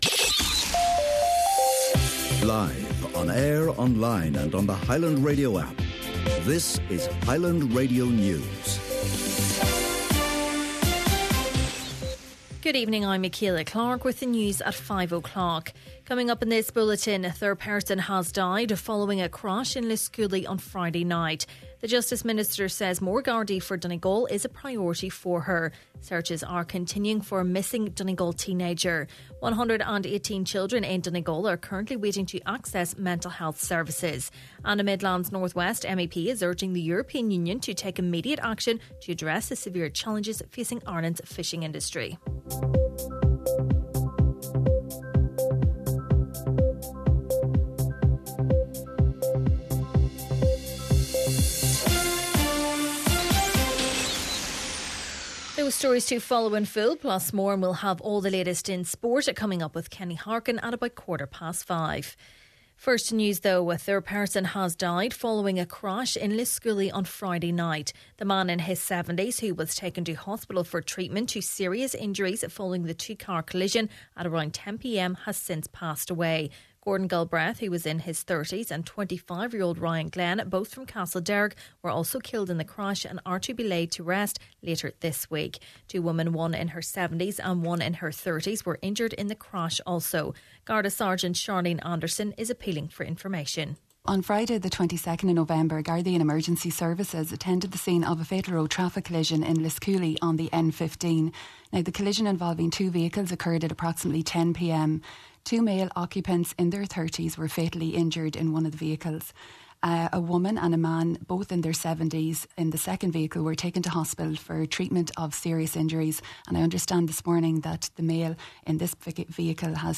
Main Evening News, Sport and Obituaries – Tuesday, November 26th